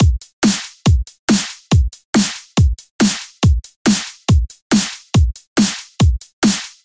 双数大鼓
Tag: 140 bpm Dubstep Loops Drum Loops 1.15 MB wav Key : Unknown